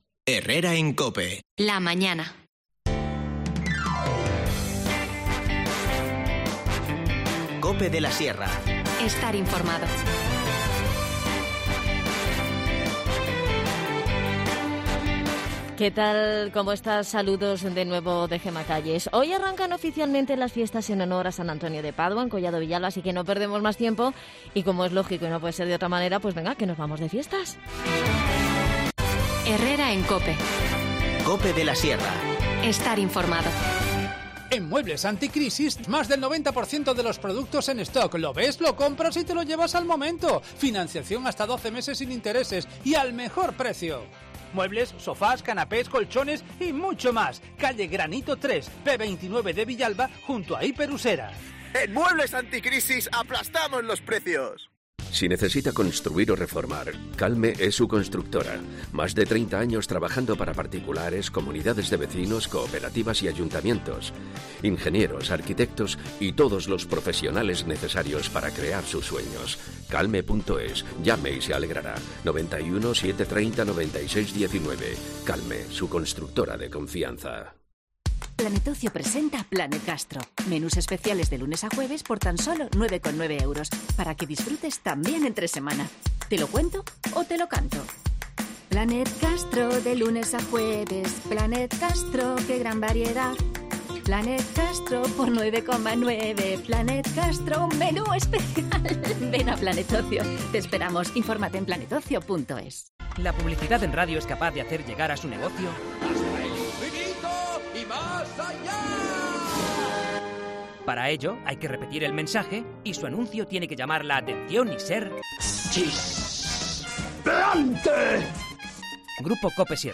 Charlamos con Adan Martínez, concejal de Comunicación.